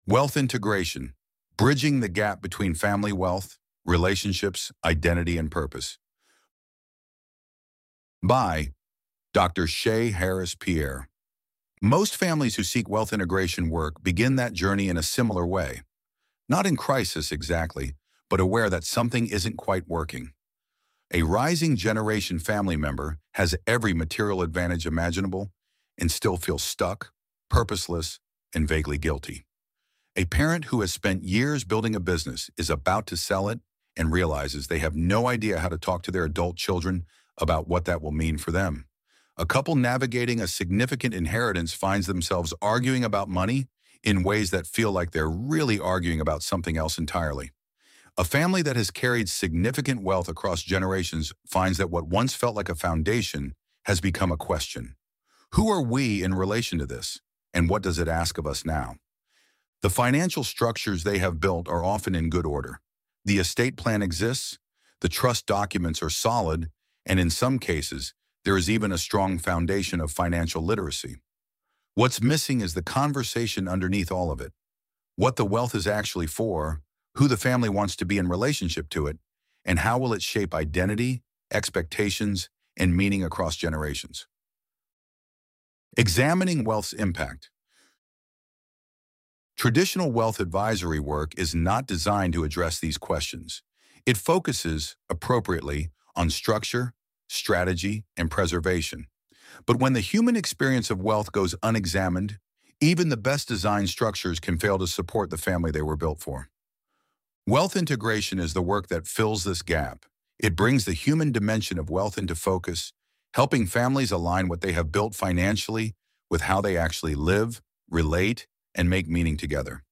7-8 mins Loading the Elevenlabs Text to Speech AudioNative Player...